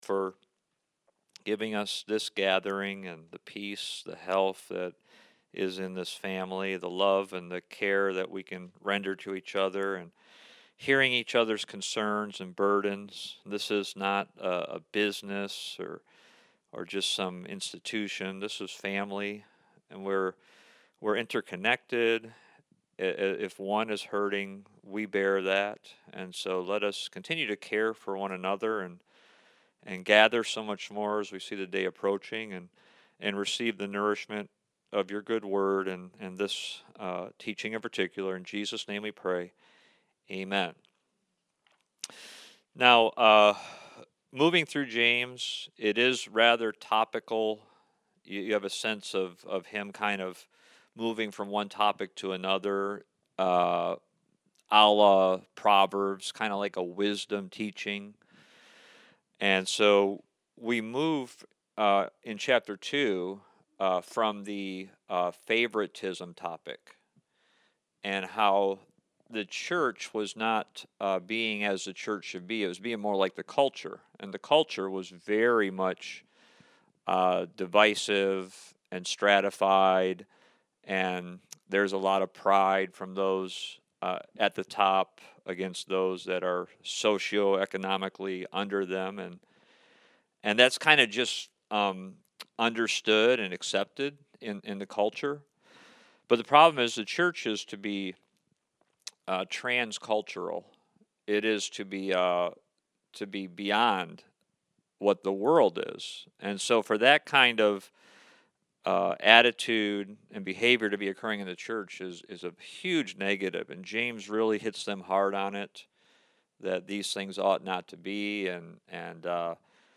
Topic: Faith with Works Service Type: Wednesday Night